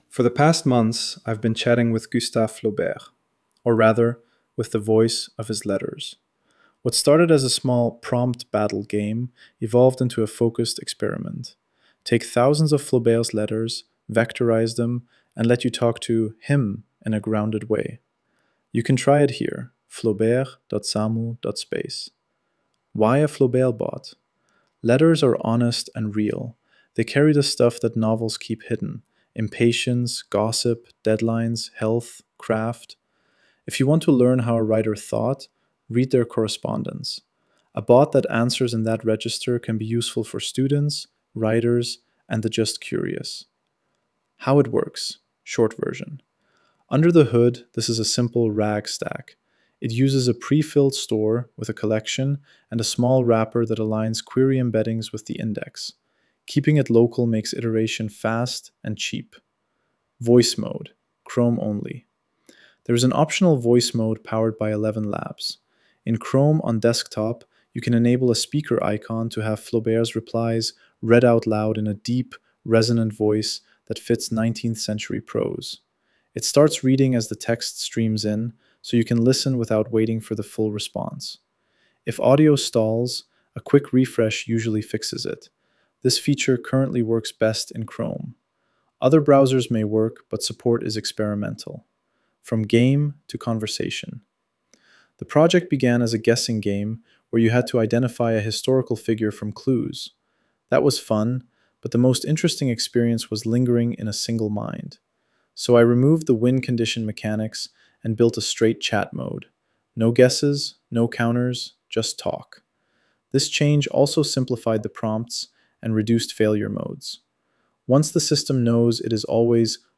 There is an optional voice mode powered by ElevenLabs. In Chrome on desktop, you can enable a speaker icon to have Flaubert’s replies read out loud in a deep, resonant voice that fits 19th-century prose.